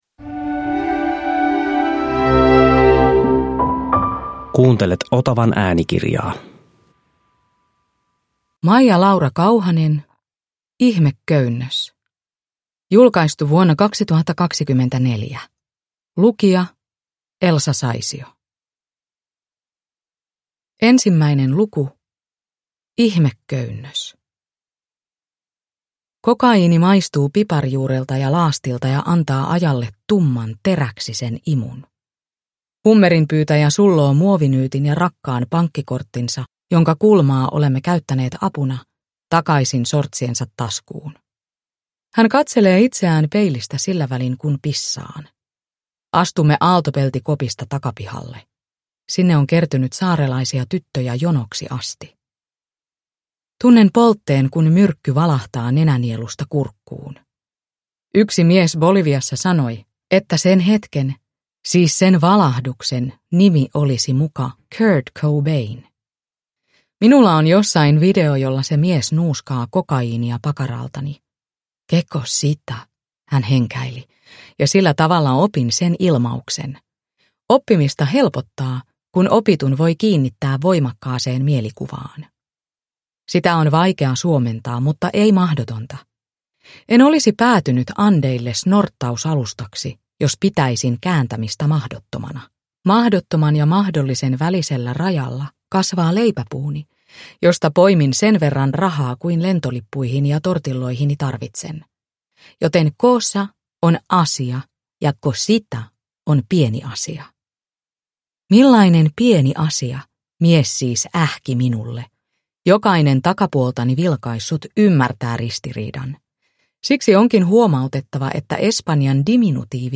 Ihmeköynnös (ljudbok) av Maija Laura Kauhanen